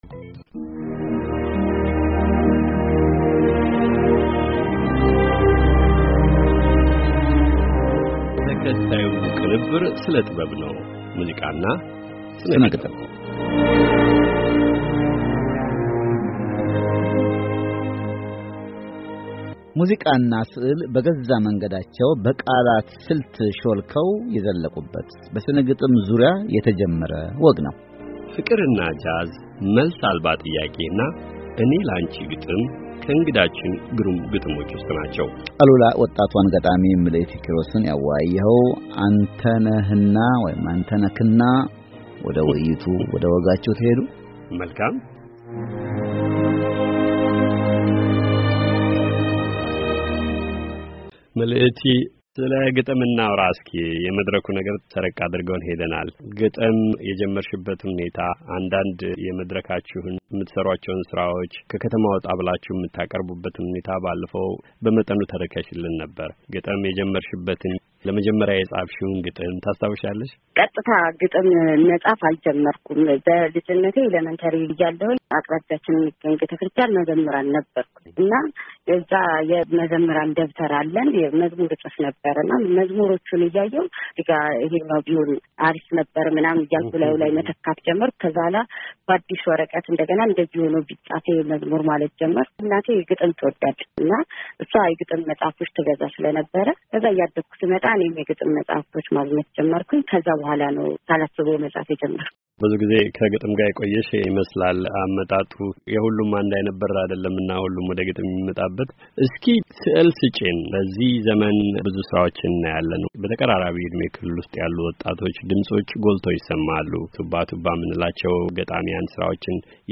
ውበት በወጣቷ ገጣሚ ዓይን - የቃለ ምልልሱን ሦሥተኛ ክፍል ከዚህ ያድምጡ